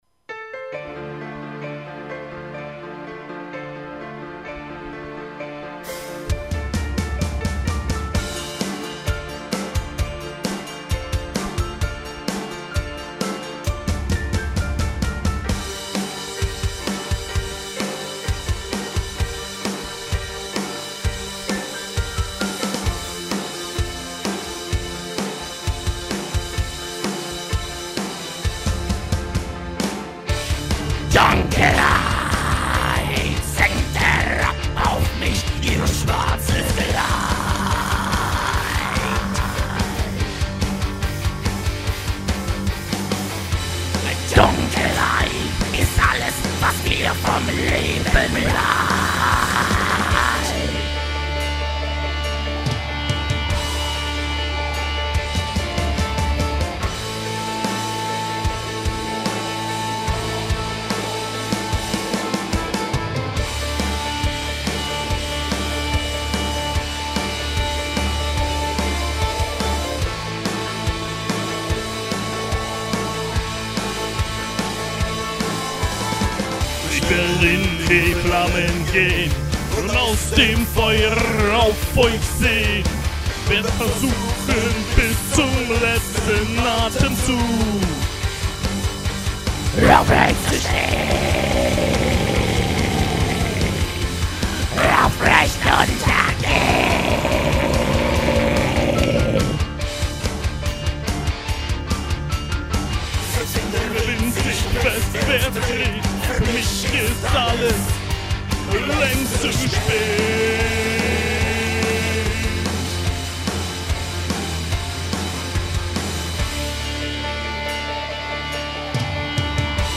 mit schlechtem Equipment und zu leisem Mikro.